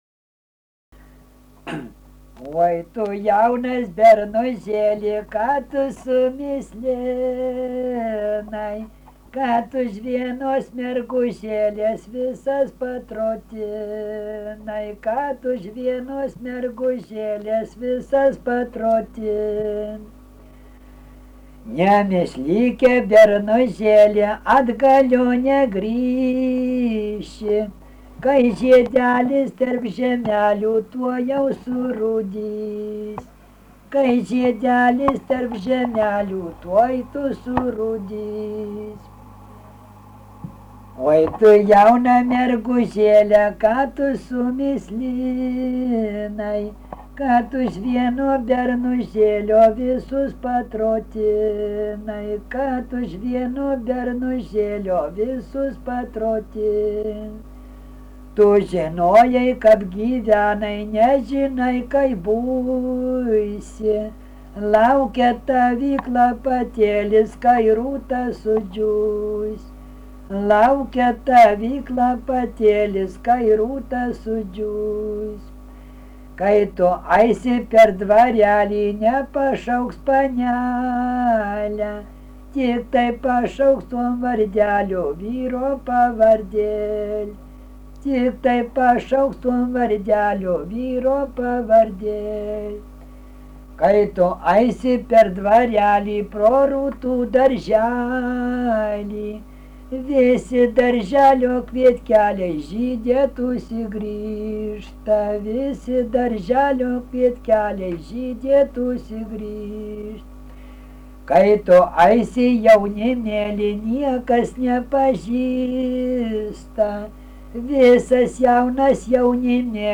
daina, vestuvių
Mikalavas
vokalinis